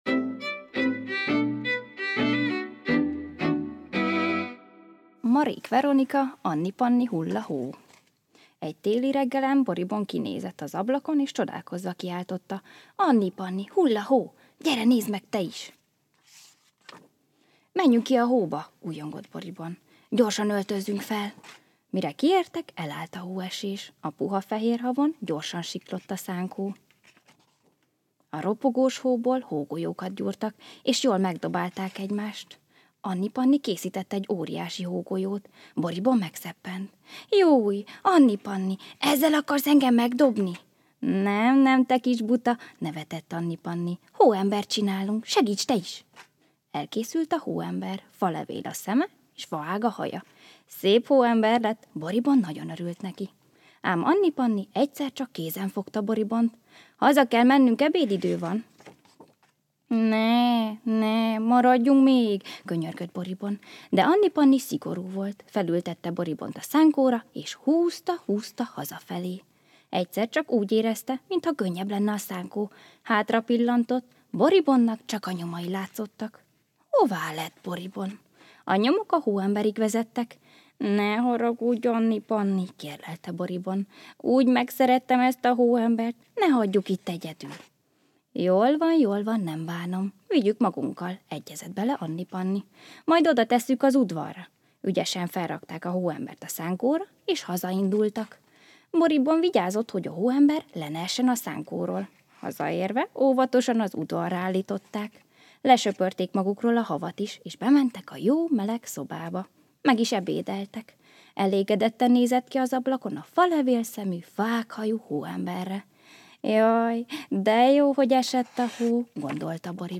Karácsonyi meglepetésként a Babapihenő bölcsöde lelkes csapata egy nagyon kedves meseösszeállítással kedveskedett a gyerekeiknek, hallgassátok szeretettel!
Babapiheno_meses_karacsonya.mp3